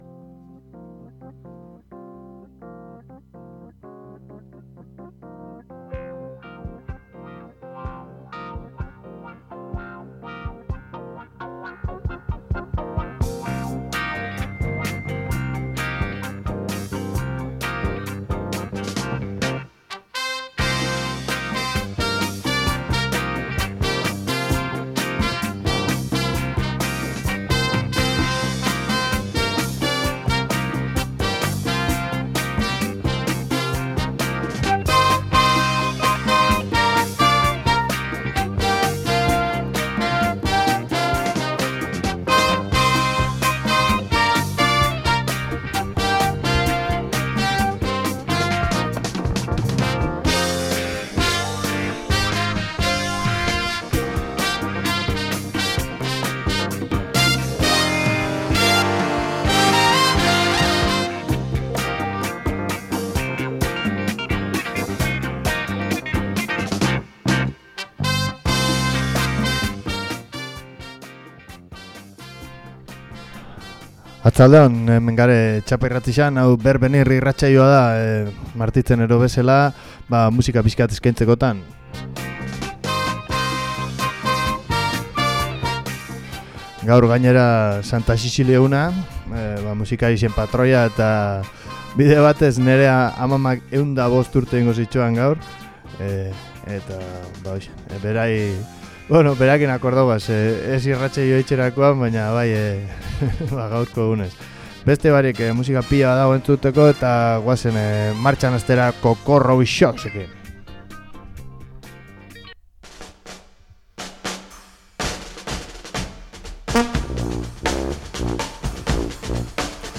Rock-a, blues-a, psikodelia, country-a…